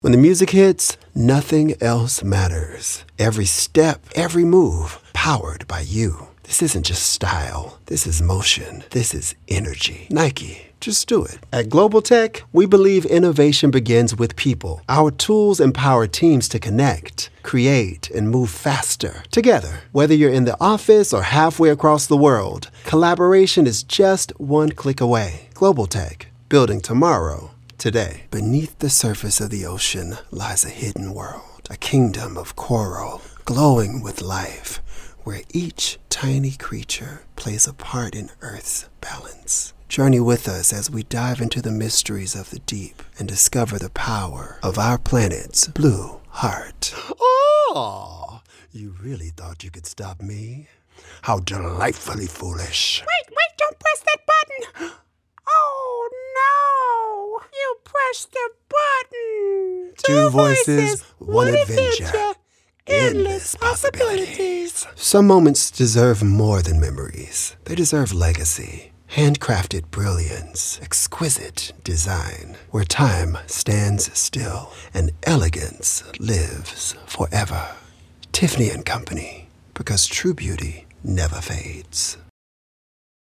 Professional voice actor, vocalist, and recording artist with a warm, grounded delivery and strong long-form consistency.
General American, Neutral US
I am a full-time voice actor and professional vocalist working from a broadcast-quality home studio.